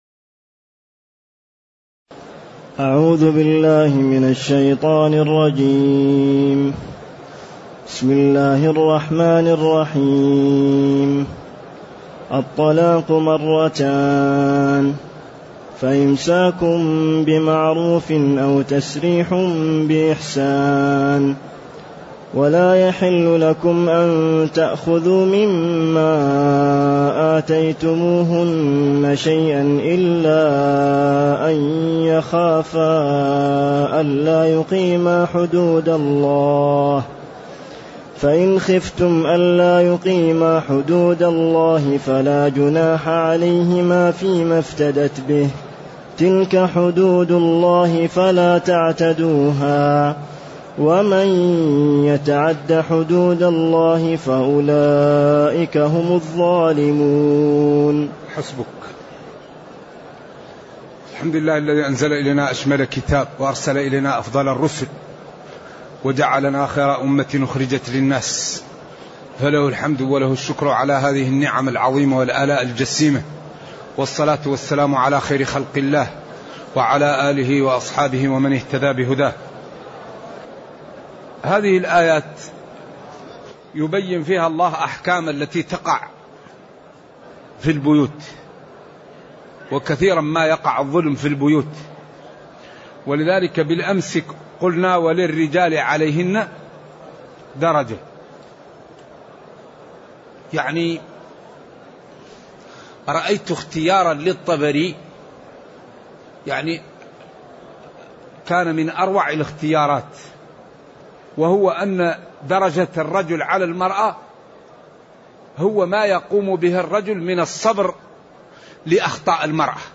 تاريخ النشر ١١ رمضان ١٤٢٨ هـ المكان: المسجد النبوي الشيخ